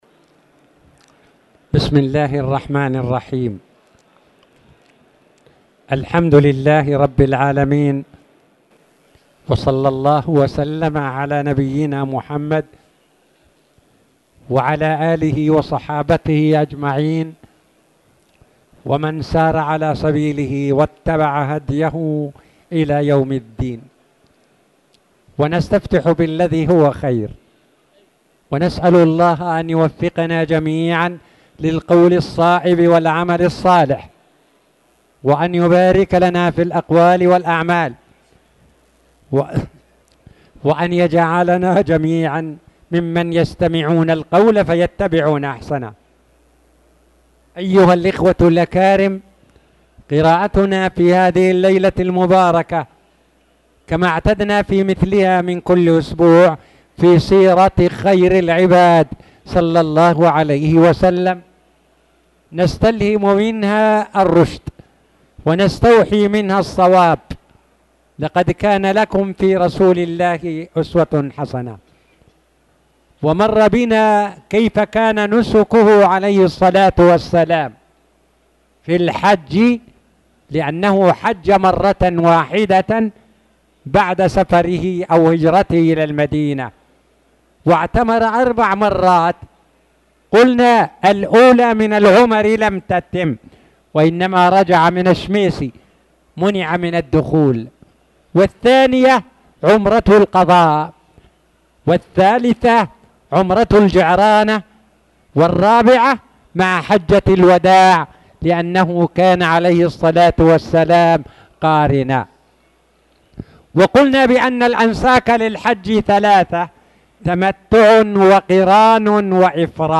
تاريخ النشر ٢٣ ربيع الأول ١٤٣٨ هـ المكان: المسجد الحرام الشيخ